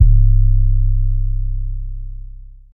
HYDRA808 (Luger).wav